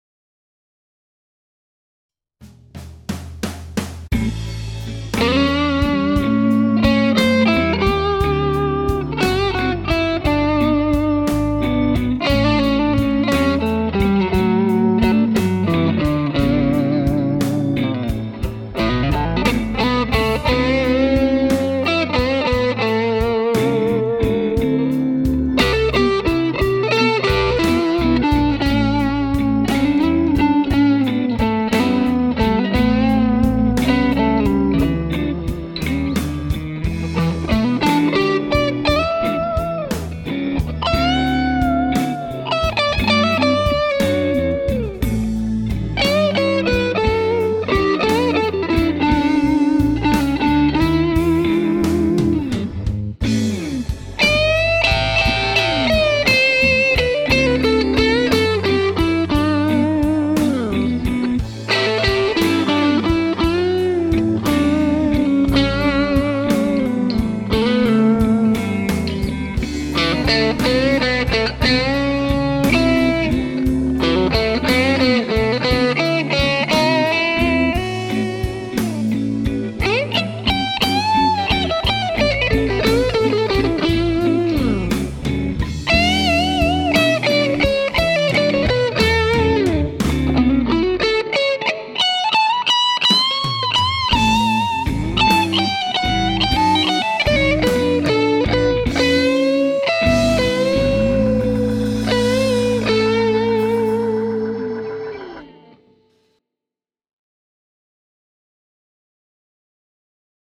I recorded a little blues solo over a standard GarageBand backing track to demonstrate.
The e609 was placed about halfway between the dome and the speaker edge about an inch away from the grille cloth.
I added a touch of reverb to the dry clip in GarageBand, but that’s it. No EQ (I don’t like to EQ my guitar parts anyway). What you hear on the clip is what I heard in my studio.